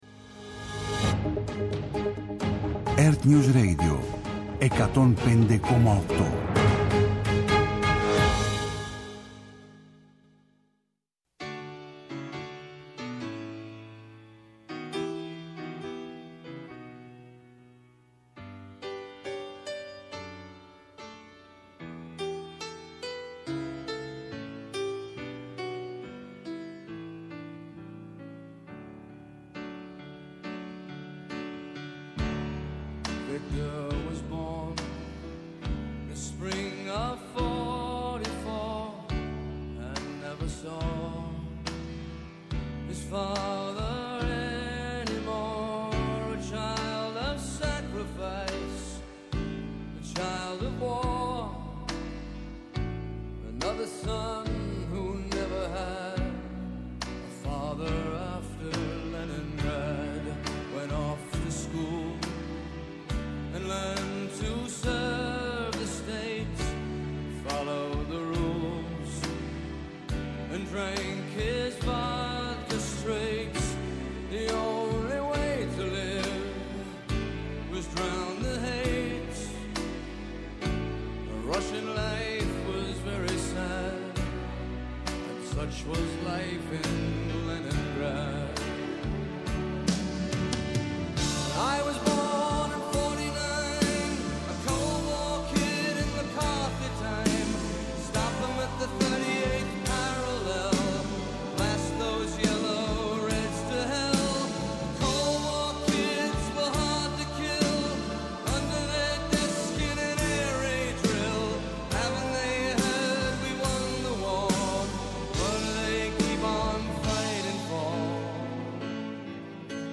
Live από Λευκό Οίκο Δηλώσεις Τραμπ